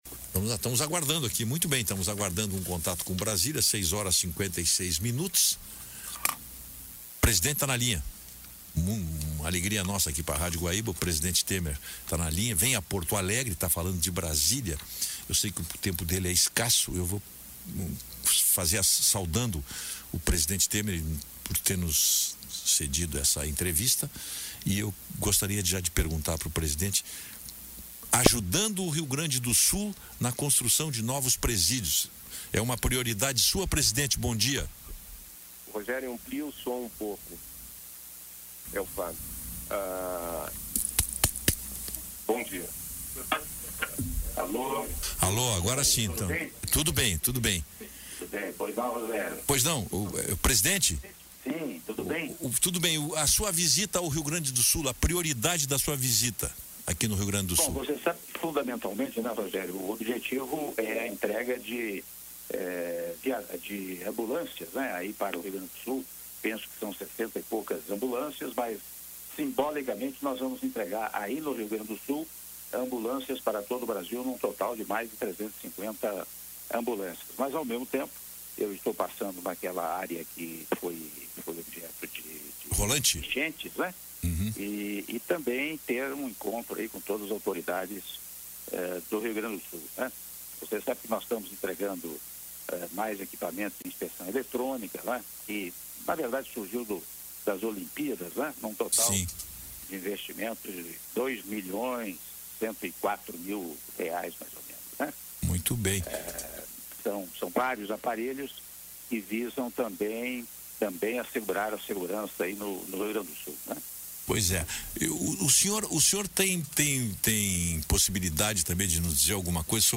Áudio da entrevista concedida pelo presidente da República, Michel Temer, à Rádio Guaíba de Porto Alegre (03min33s)